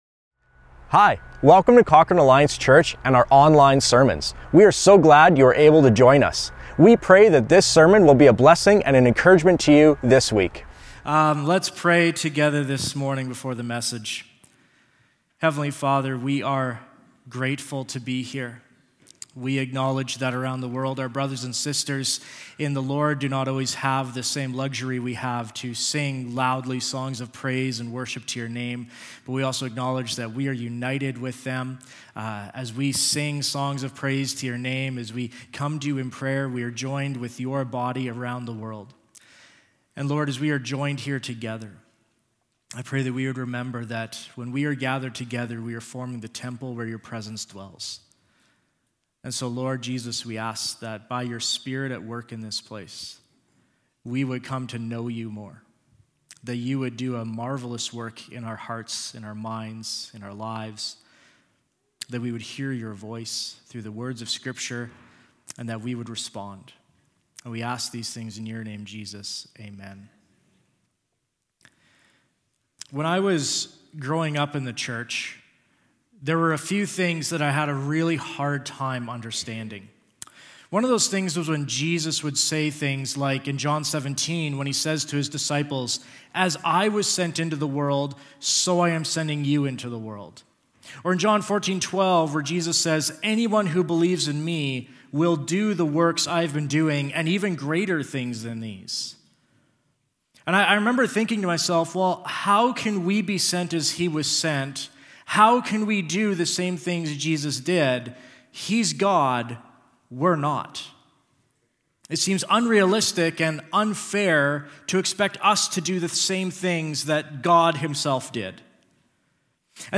Cochrane Alliance Church Sermons | Cochrane Alliance Church